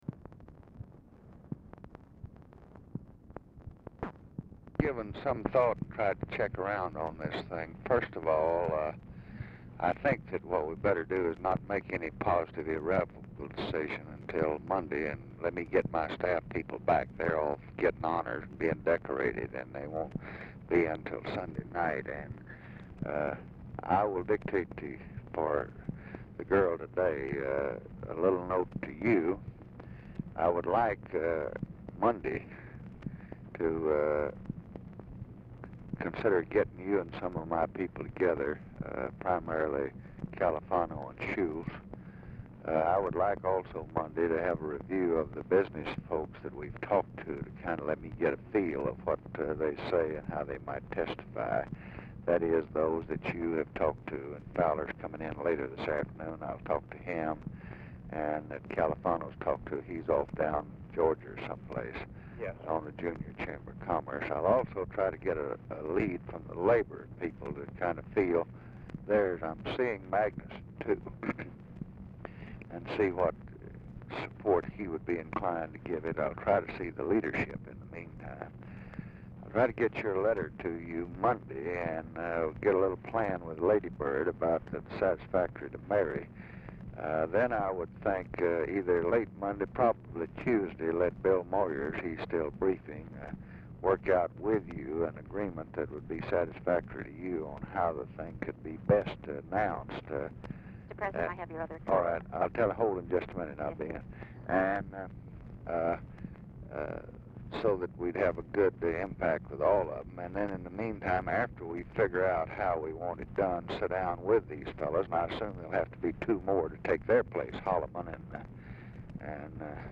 Telephone conversation # 11352, sound recording, LBJ and JOHN CONNOR, 1/14/1967, 8:37AM
RECORDING STARTS AFTER CONVERSATION HAS BEGUN; TELEPHONE OPERATOR INTERRUPTS CALL TO TELL LBJ HE HAS ANOTHER CALL
Format Dictation belt